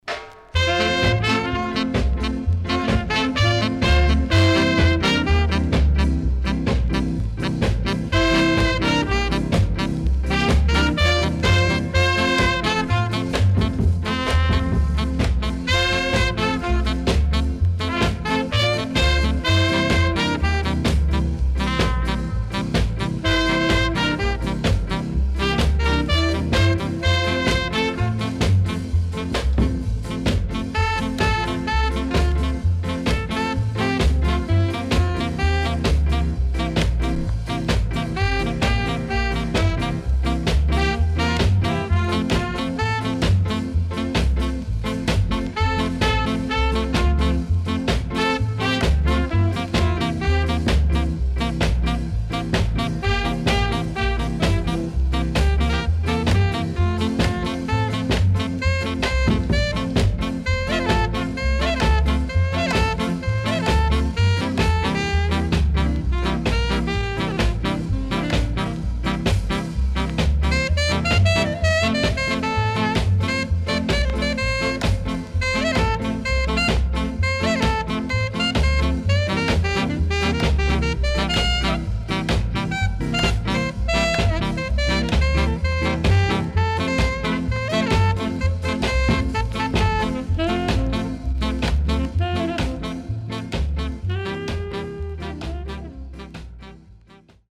Good Rocksteady & Ska Inst.W-Side Good
SIDE A:こまかいチリノイズ入ります。